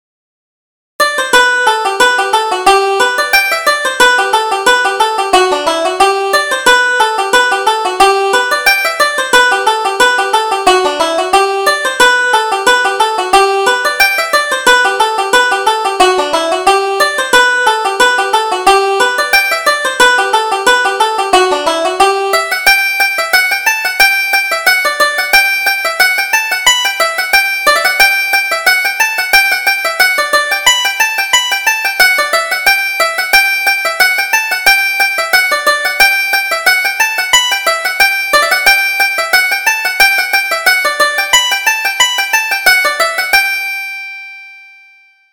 Reel: The Wedding